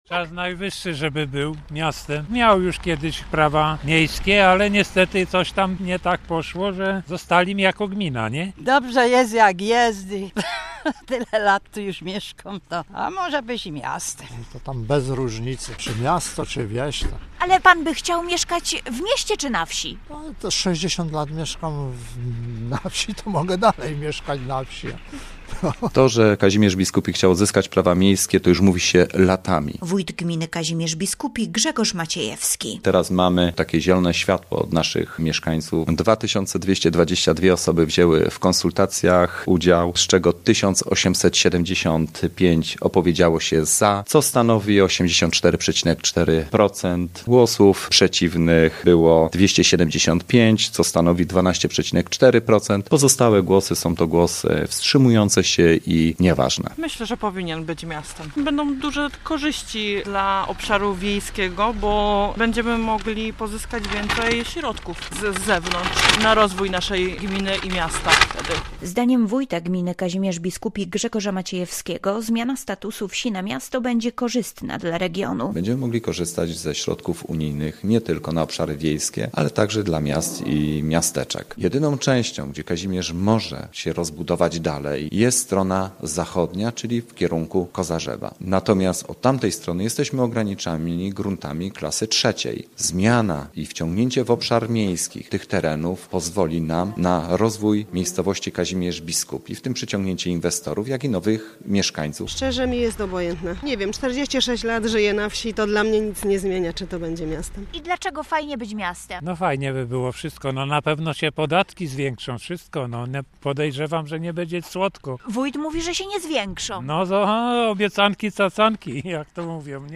Nasza reporterka zapytała mieszkańców Kazimierza Biskupiego, czy chcą, by ich miejscowość stała się miastem.